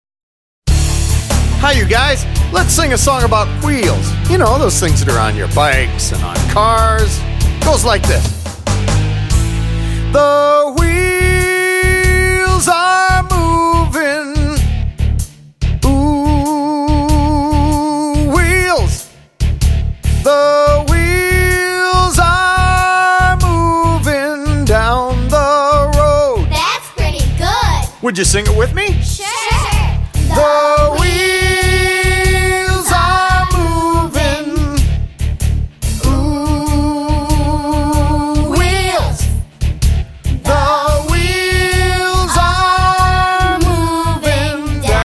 -23 simple, catchy songs
-Kids and adults singing together and taking verbal turns